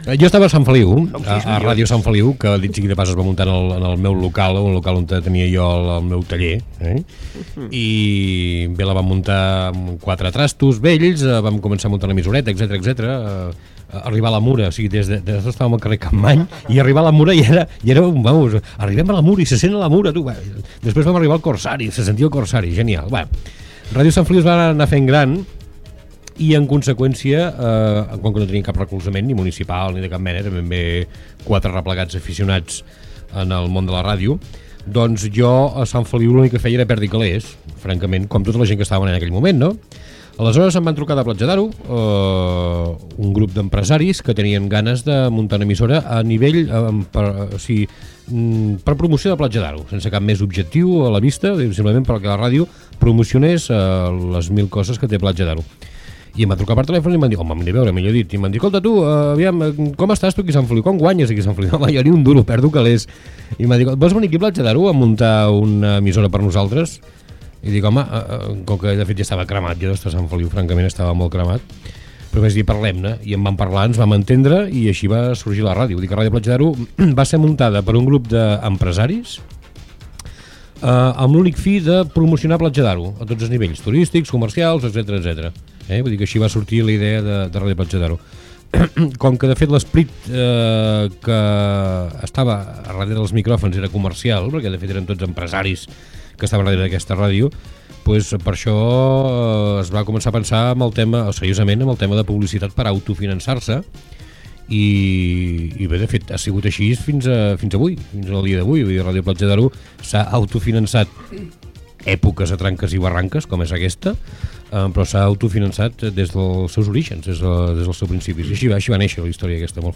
FM
Fragment extret de l'arxiu sonor de Ràdio Platja d'Aro